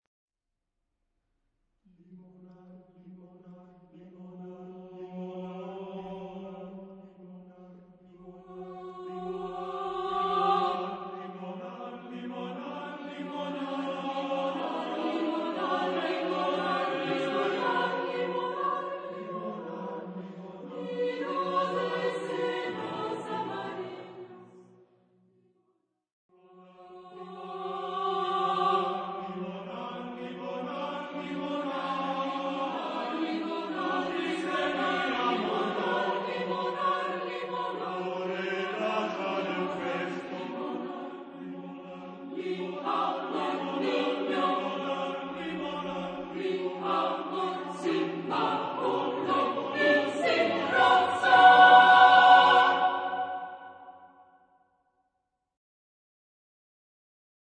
SSATB (5 voices mixed) ; Full score.
Poetical song. Choir.
Tonality: F sharp minor